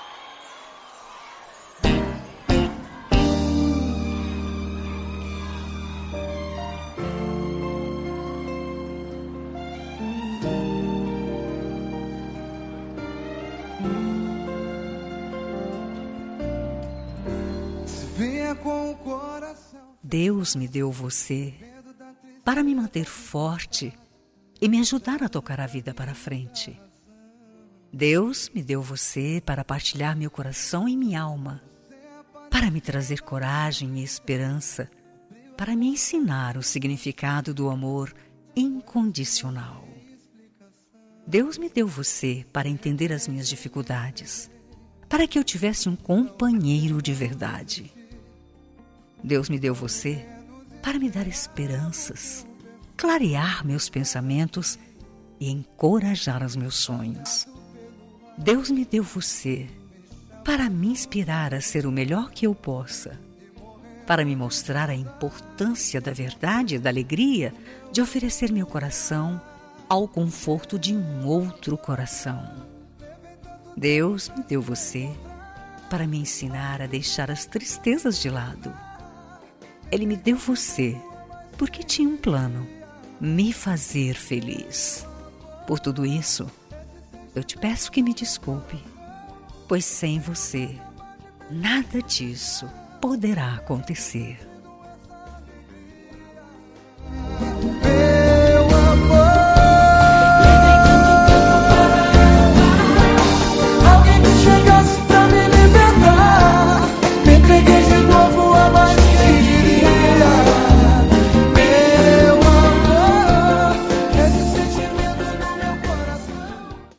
Telemensagem de Reconciliação – Voz Feminina – Cód: 7541